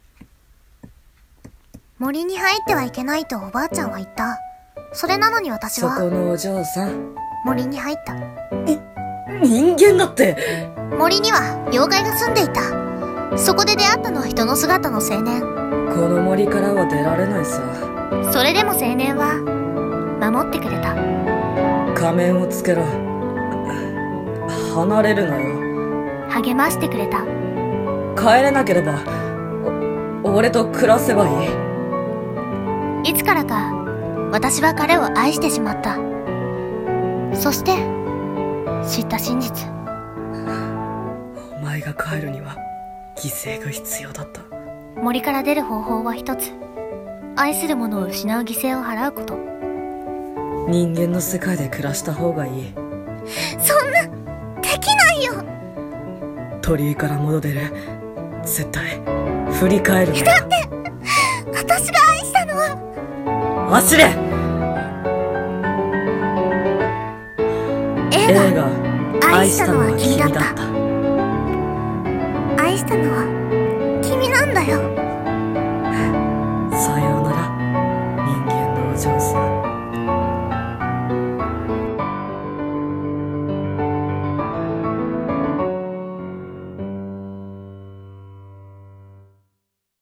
【映画予告風声劇】愛したのは君だった 声劇